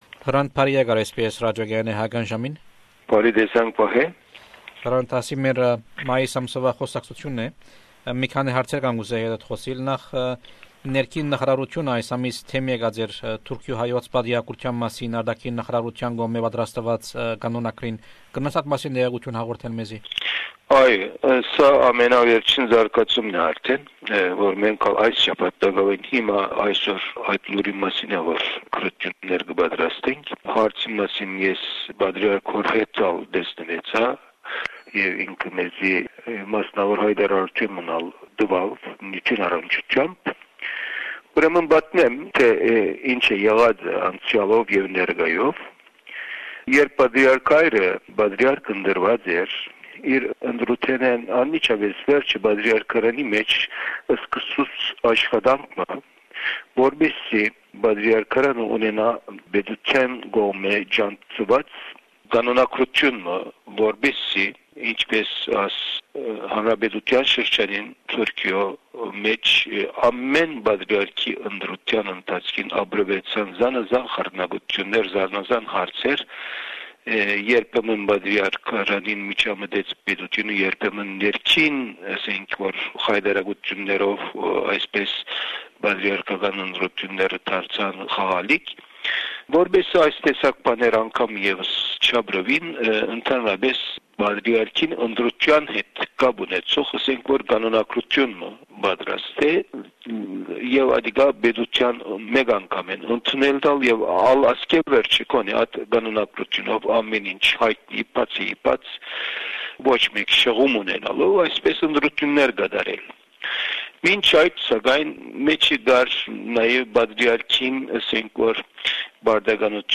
In memory of the 10th anniversary of the assassination of Hrant Dink, we bring you the interviews he gave to SBS Radios Armenian program. This interview was done in May 2004.
Hrant Dink during at SBS Radio station in Melbourne Source: SBS Armenian